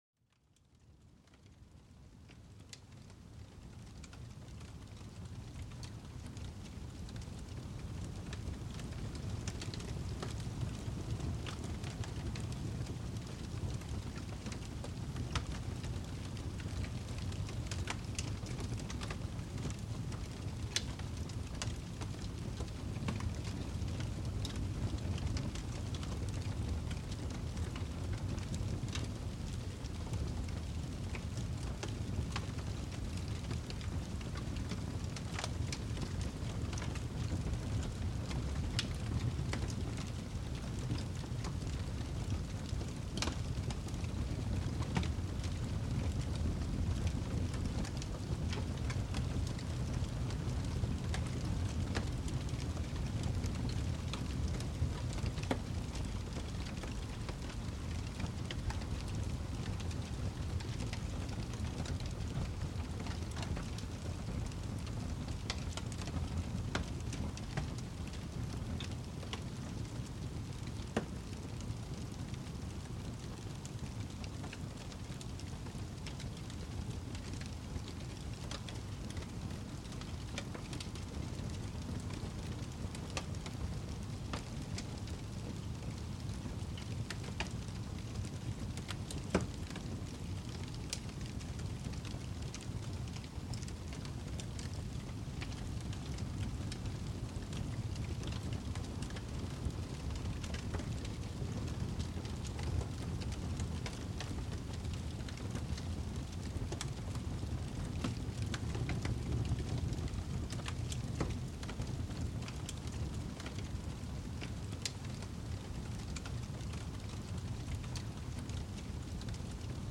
Chaleur d'un Feu Crépitant pour une Détente Profonde
Écoutez le doux crépitement d'un feu de camp qui réchauffe et apaise l'esprit. Chaque crépitement vous transporte dans une ambiance intime et réconfortante, idéale pour évacuer le stress et vous plonger dans une relaxation profonde.